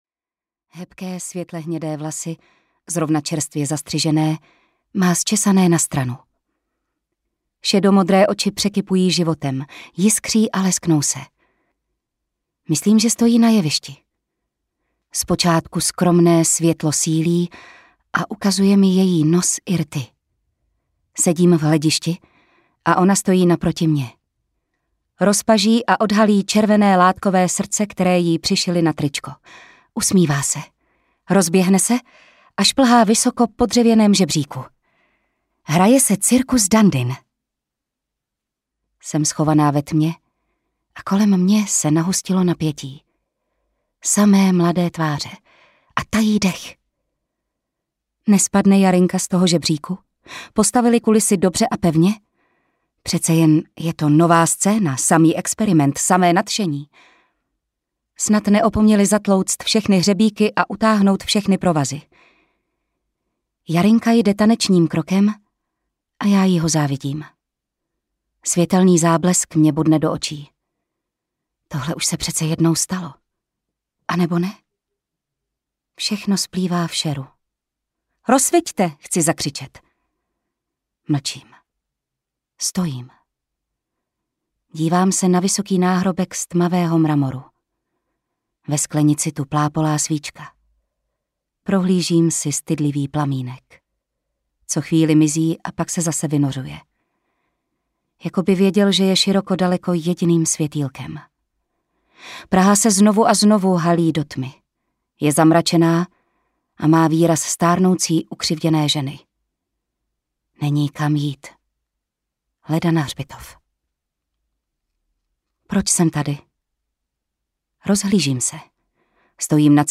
Herečka audiokniha
Ukázka z knihy